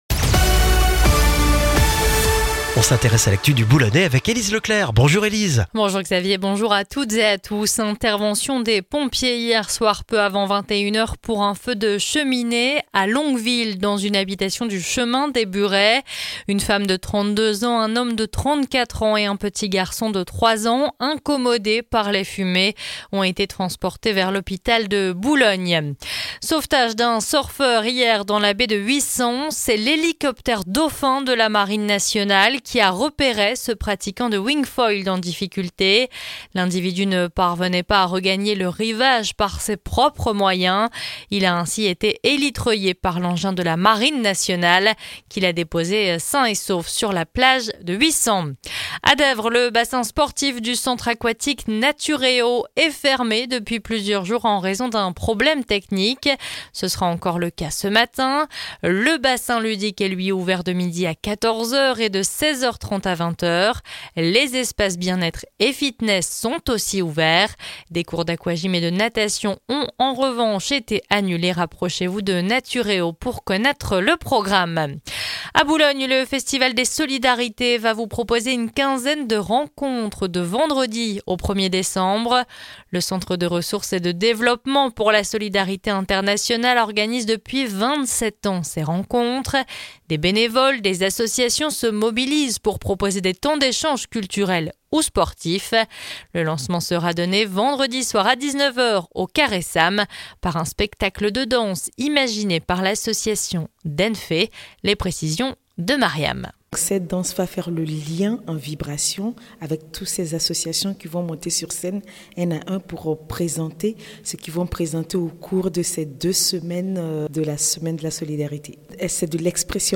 Le journal du mercredi 13 novembre dans le Boulonnais